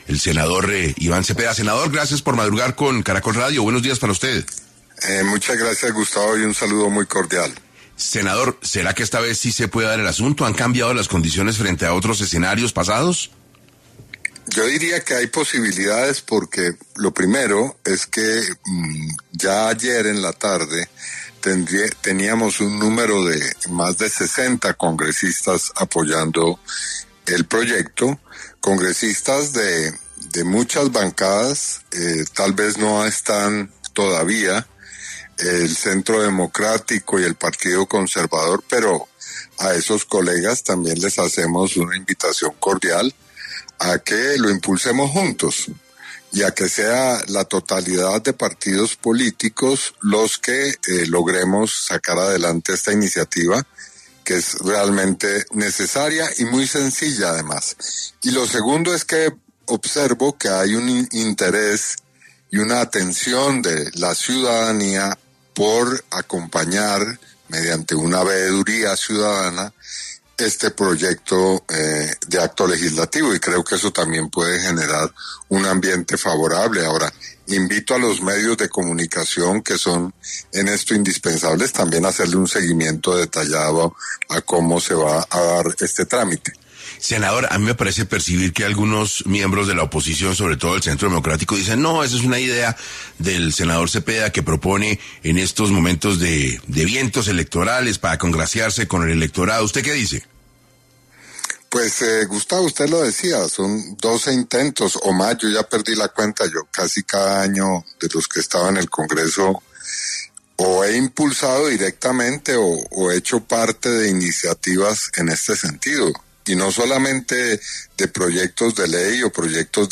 En 6AM de Caracol Radio estuvo el senador Iván Cepeda, para hablar sobre por qué esta vez sí pasaría el proyecto con el que buscan reducir el salario de los congresistas.